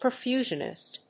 • IPA(key): /pə(ɹ)ˈfjuːʒənɪst/